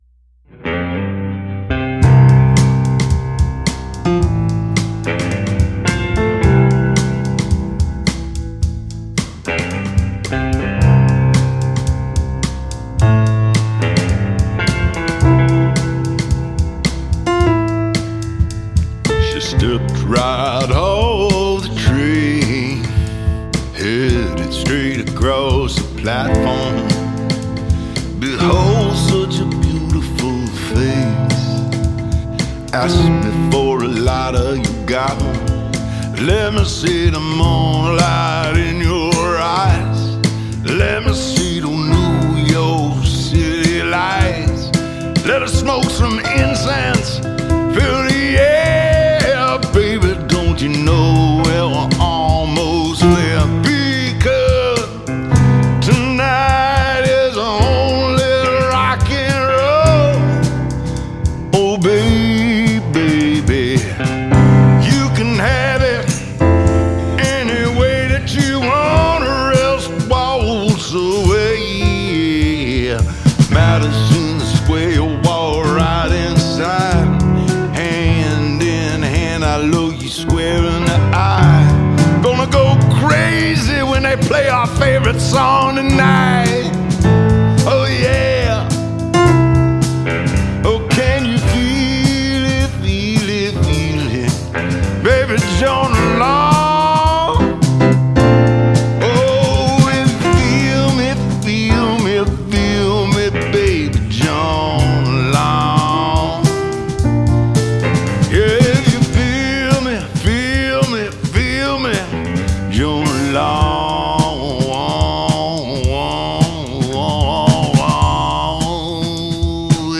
Genre: Rock, Jam Band, Americana, Roots.